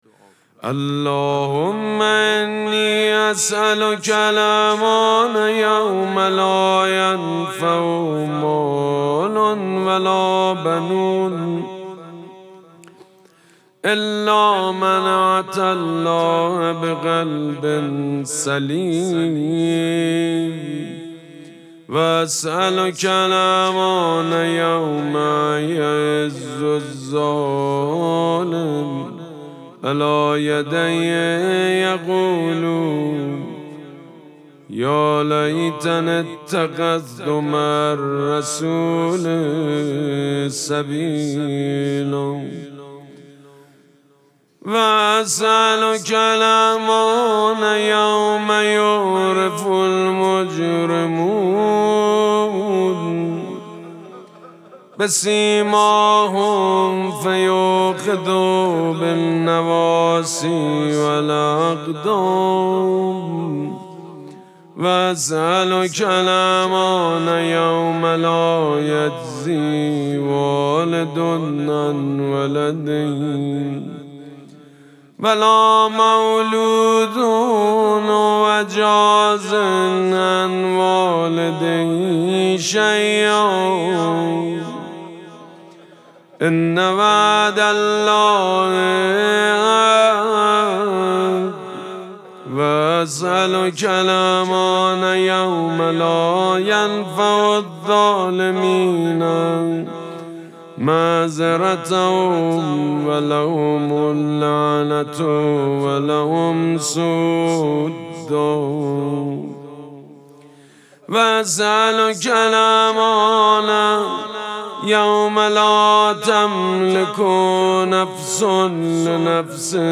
مراسم مناجات شب سوم ماه مبارک رمضان دوشنبه ۱۳ اسفند ماه ۱۴۰۳ | ۲ رمضان ۱۴۴۶ حسینیه ریحانه الحسین سلام الله علیها
سبک اثــر مناجات مداح حاج سید مجید بنی فاطمه